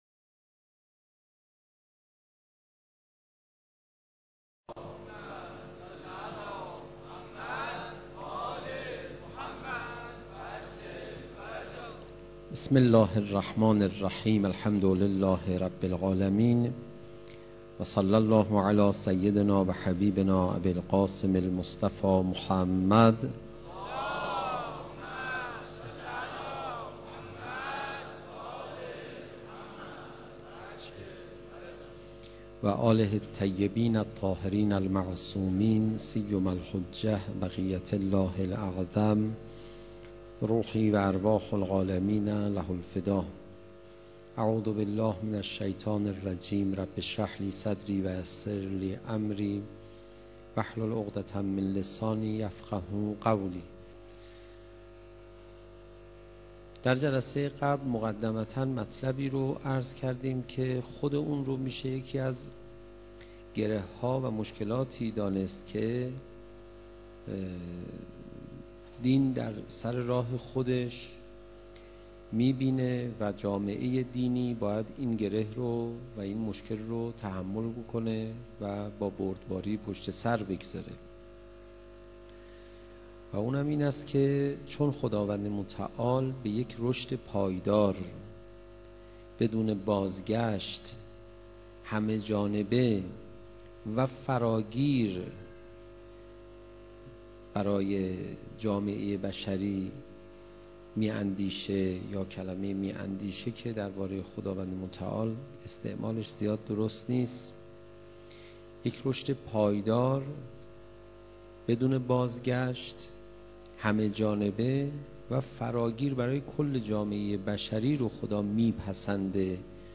سخنرانی حجت الاسلام پناهیان درمورد مشکلات دینداری